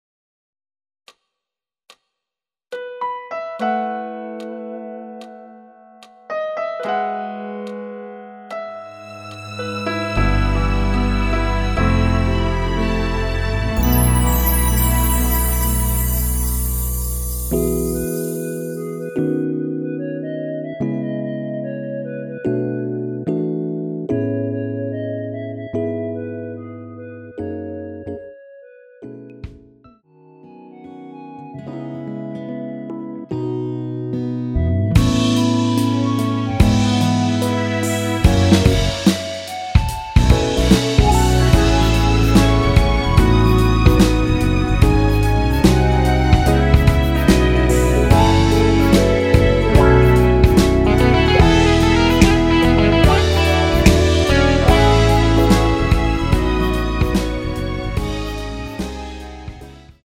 원키에서(-1)내린 멜로디 포함된 MR입니다.
앞부분30초, 뒷부분30초씩 편집해서 올려 드리고 있습니다.
곡명 옆 (-1)은 반음 내림, (+1)은 반음 올림 입니다.
(멜로디 MR)은 가이드 멜로디가 포함된 MR 입니다.